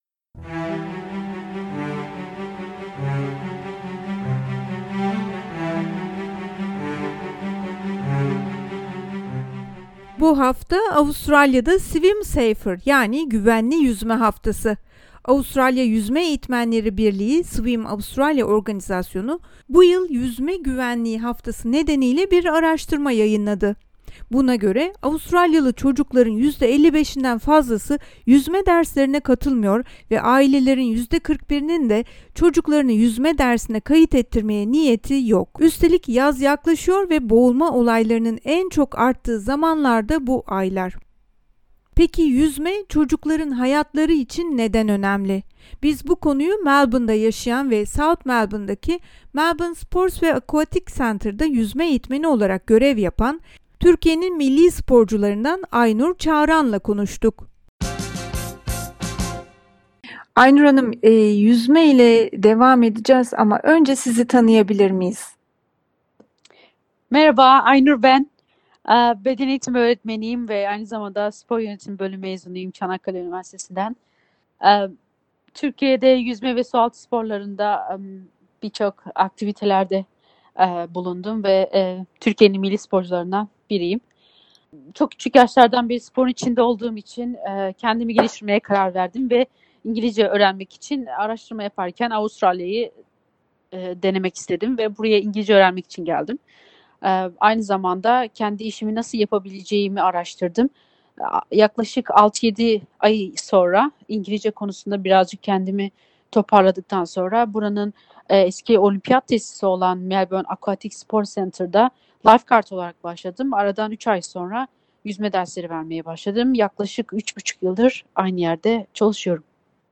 Güvenli Yüzme Haftası dolayısıyla bir röportaj gerçekleştirdiğimiz yüzme eğitmeni, bu sporun çocukların hayatına nasıl pozitif etkiler kattığını anlatırken aynı zamanda, Avustralya'ya geliş öyküsünü ve tedbirler sırasında nasıl zorluklar yaşadığını SBS Türkçe'ye anlattı.